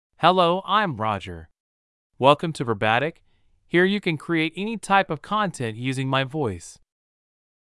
MaleEnglish (United States)
RogerMale English AI voice
Voice sample
Listen to Roger's male English voice.
Male
Roger delivers clear pronunciation with authentic United States English intonation, making your content sound professionally produced.